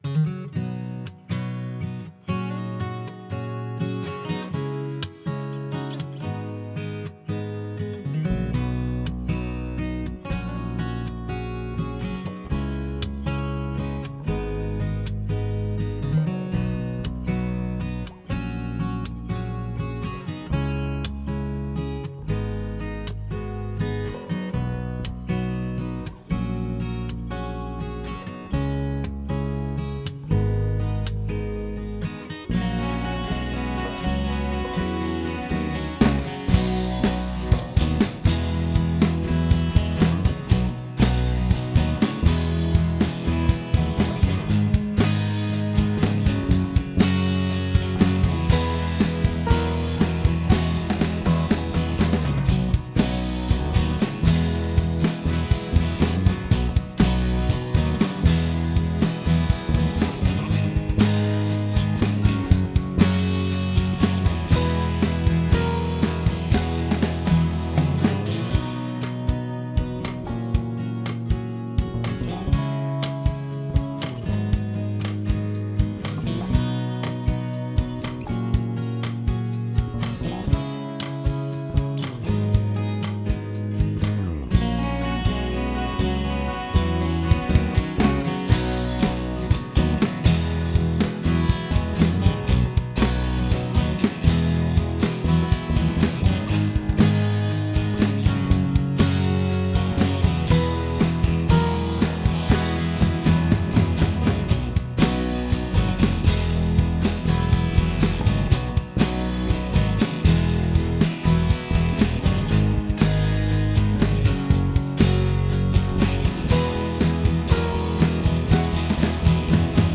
Key of B - Track Only - No Vocal